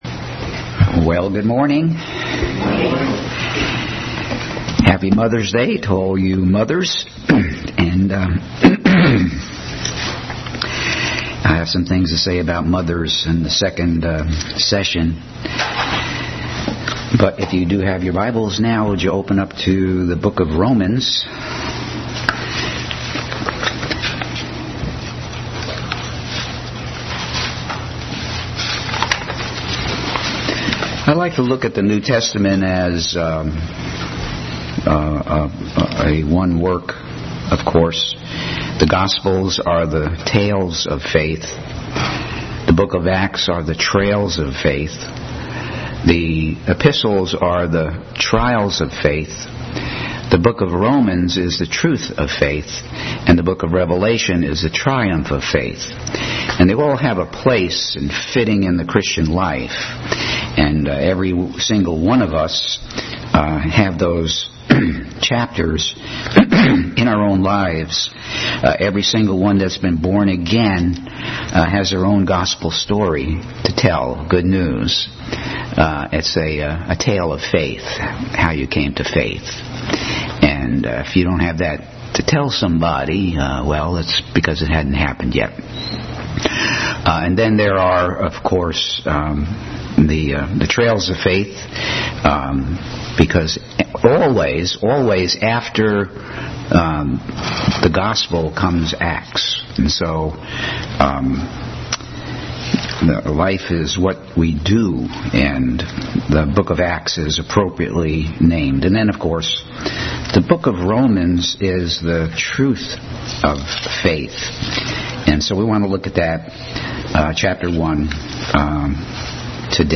Bible Text: Romans 1:1-32, Psalms 34;21 | Adult Sunday School continuing study in the book of Romans.
Psalms 34;21 Service Type: Sunday School Bible Text